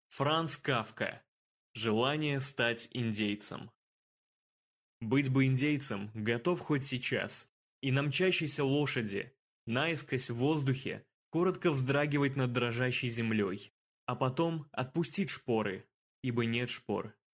Аудиокнига Желание стать индейцем | Библиотека аудиокниг